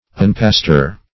Search Result for " unpastor" : The Collaborative International Dictionary of English v.0.48: Unpastor \Un*pas"tor\, v. t. [1st pref. un- + pastor.]